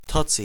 TOTSE (/ˈtɒtsi/